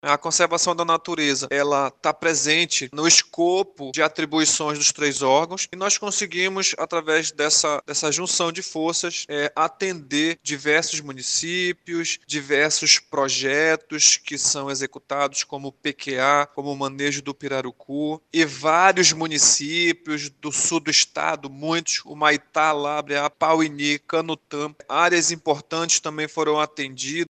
As ações abrangeram o monitoramento de lagos, praias e florestas, além do diálogo direto com as comunidades locais, explica o superintendente do Ibama no Amazonas Joel Bentes de Araújo Filho.